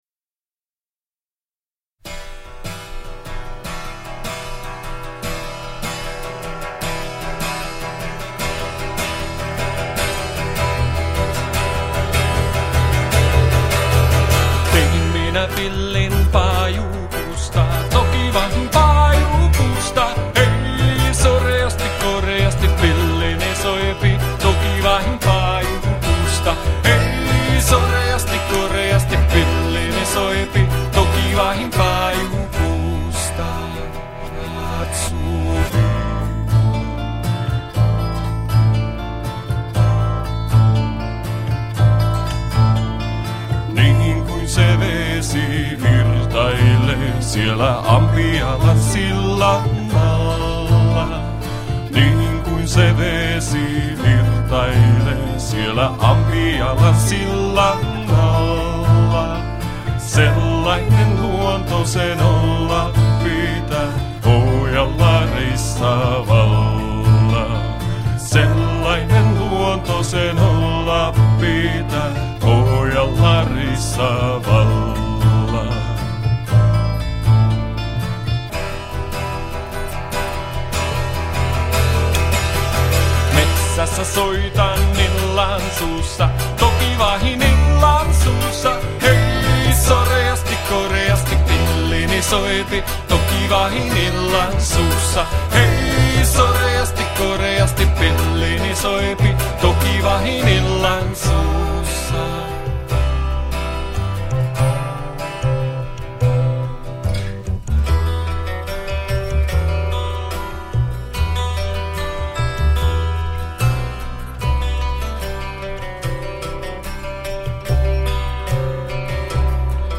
laulu, kontrabasso
akustinen kitara / mandoliini, taustalaulu
akustinen kitara, taustalaulu